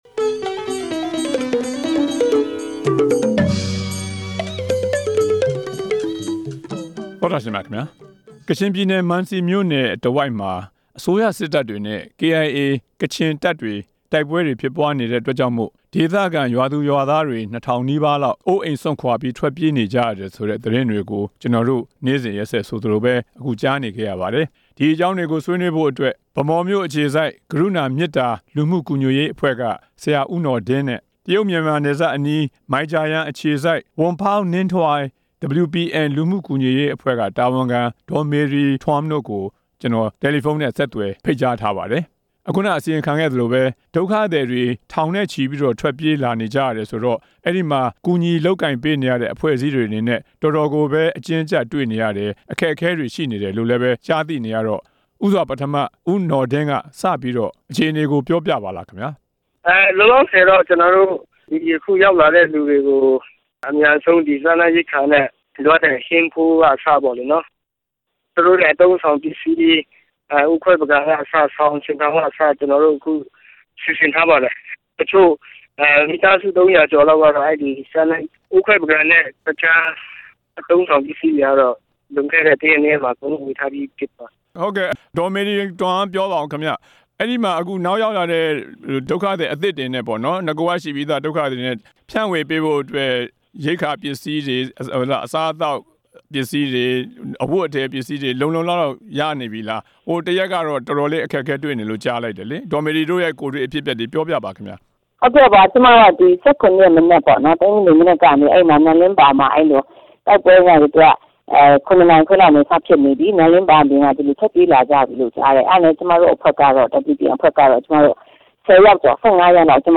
မန်စီမြို့နယ် ဒုက္ခသည်တွေနဲ့ ပတ်သက်ပြီး ဆွေးနွေးချက်